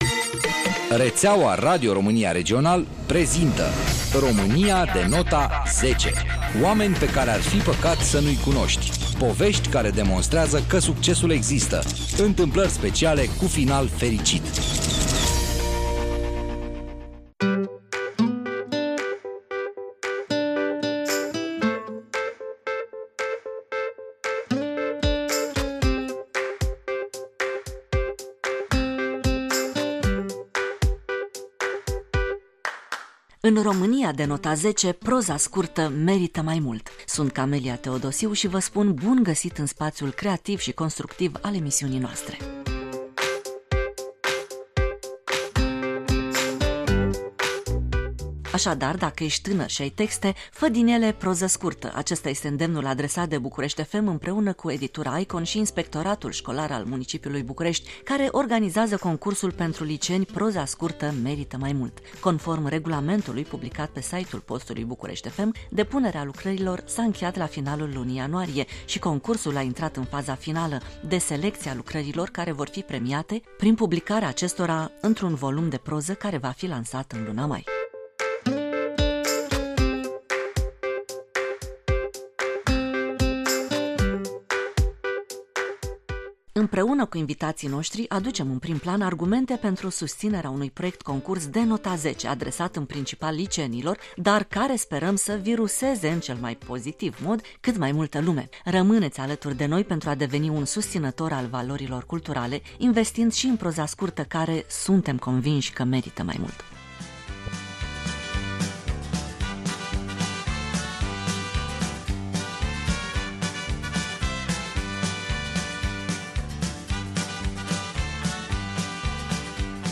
Împreună cu invitaţii noştrii, aducem în prim plan argumente pentru susţinerea unui proiect concurs de nota 10 adresat în principal liceenilor dar care sperăm să “viruseze”, în cel mai pozitiv mod, cât mai multă lume.